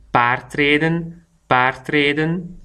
PRONONCIATION
paardrijden.mp3